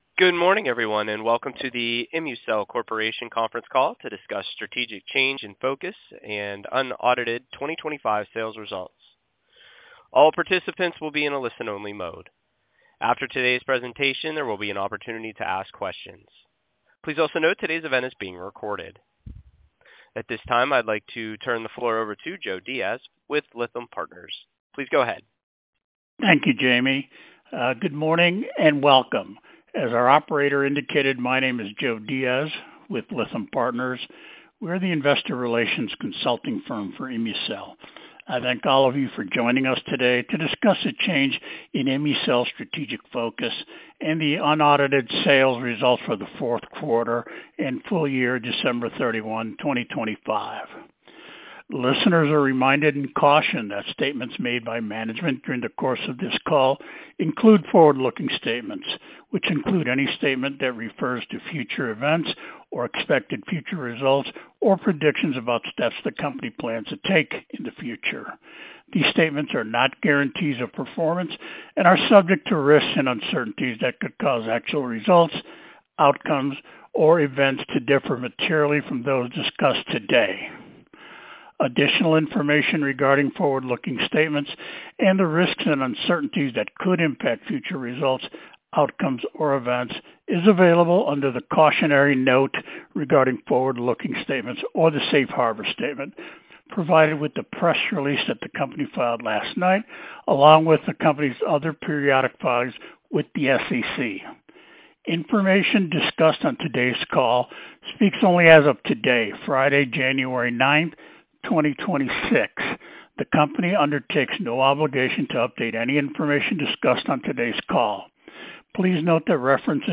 Most Recent Conference Call